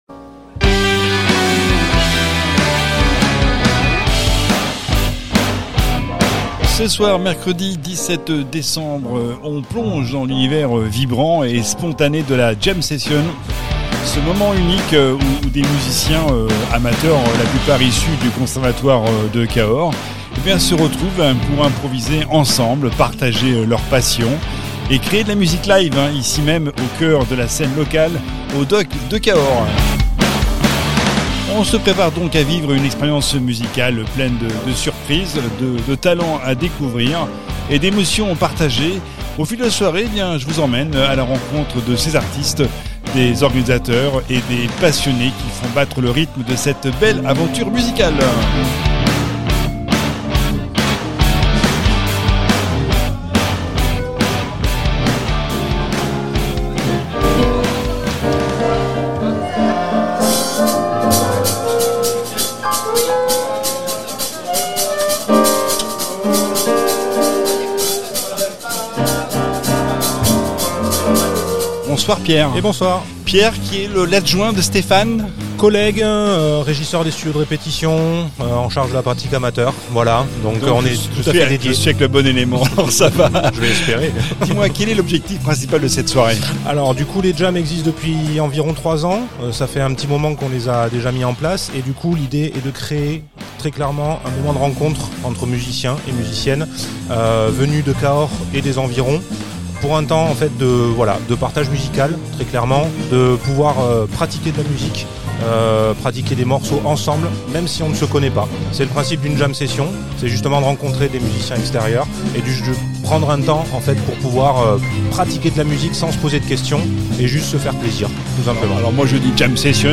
La 3e édition des Jam Sessions qui a eu lieu mercredi 17 décembre aux Les Docks - Scène de Musiques Actuelles à Cahors ! Un moment unique où musiciens amateurs, pour la plupart issus du Conservatoire de Cahors, se sont retrouvés pour improviser, partager leur passion et créer de la musique live au cœur de la scène locale.